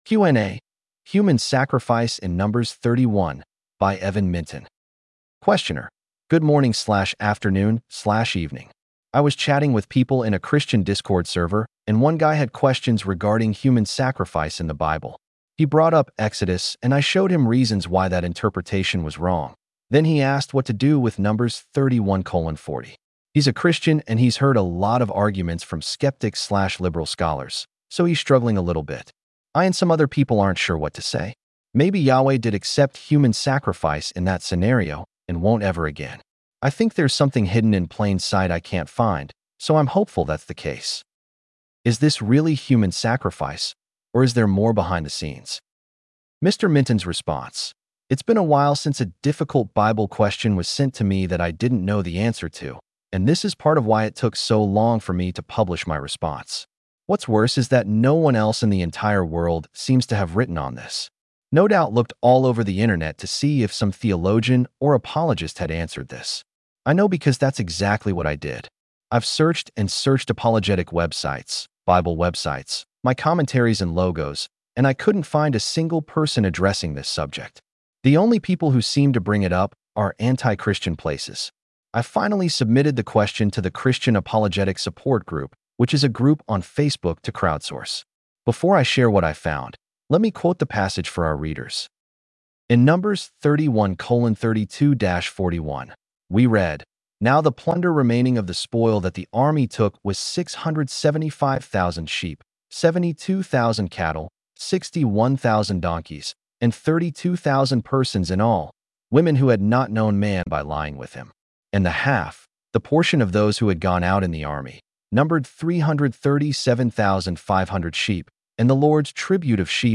Audio accessibility for this blog post is powered by Microsoft Text-to-Speech technology.